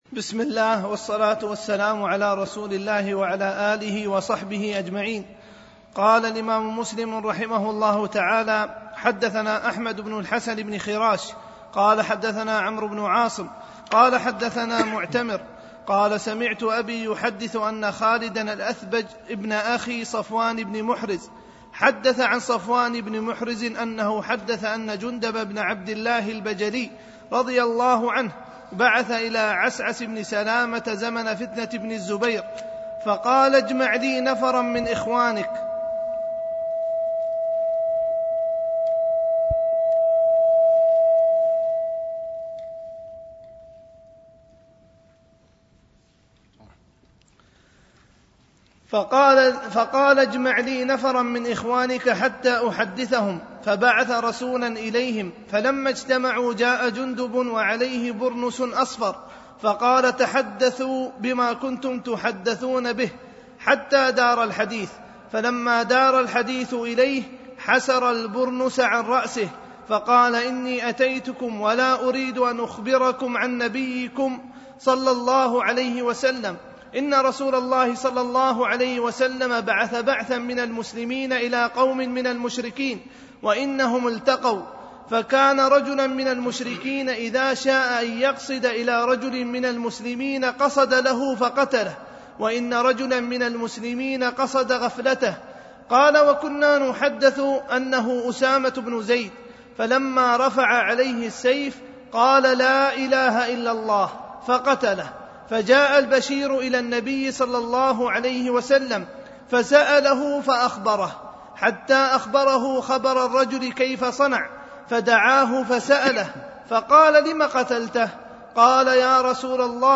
دروس مسجد عائشة (برعاية مركز رياض الصالحين ـ بدبي)